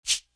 Still Feel Me Shaker.wav